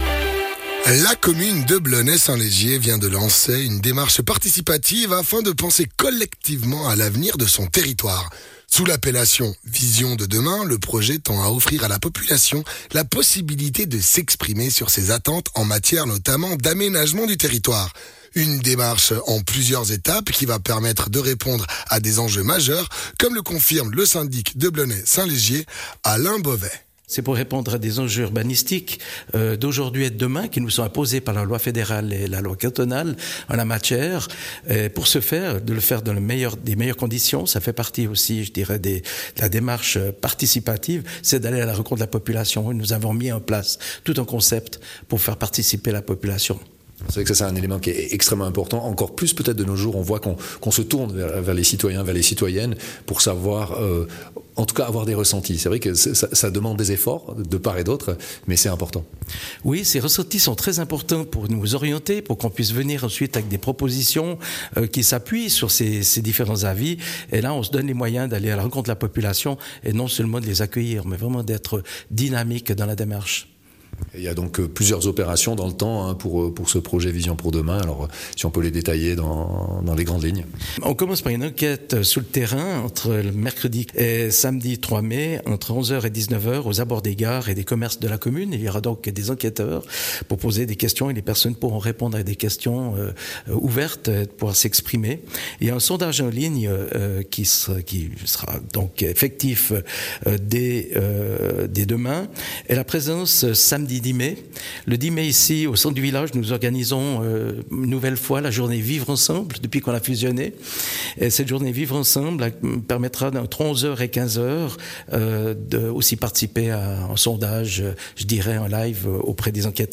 Intervenant(e) : Alain Bovay, Syndic de Blonay-St-Légier